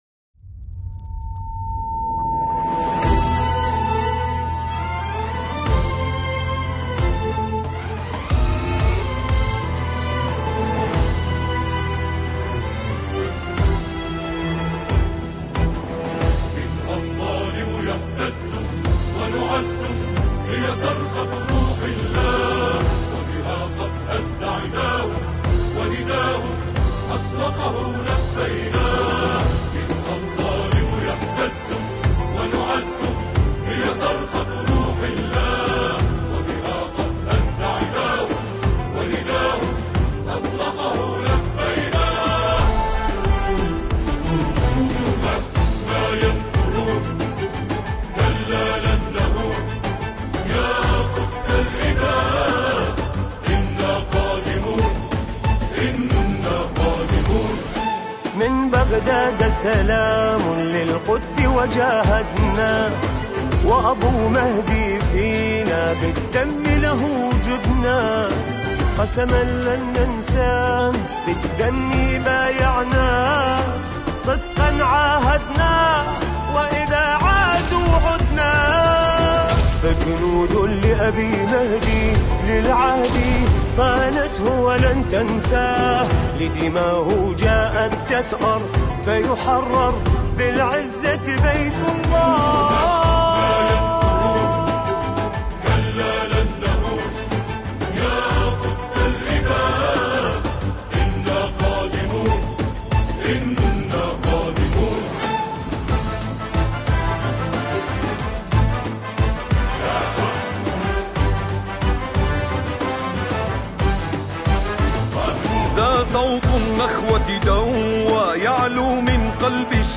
سرود انا قادمون